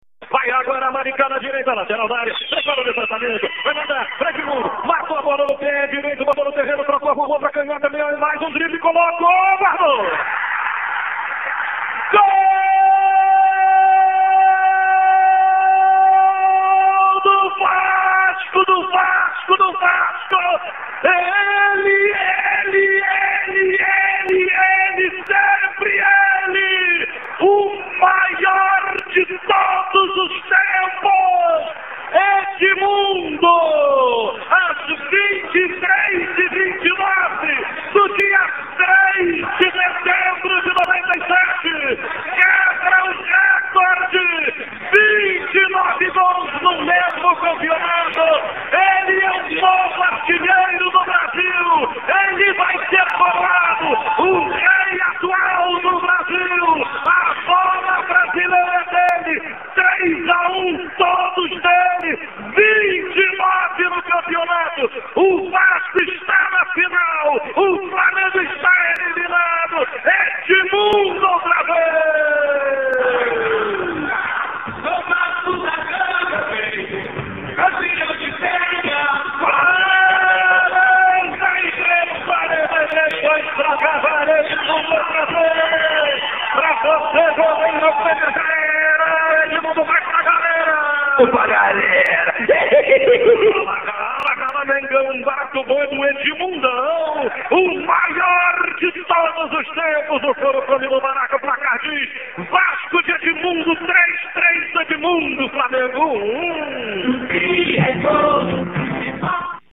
aqui para ouvir o gol histórico na emocionante narração de Luiz Penido, da Super Rádio Tupi.
Fonte: NETVASCO (texto, ficha), Rádio Tupi/digitalizado por NETVASCO (áudio), TV Globo/Youtube (vídeo)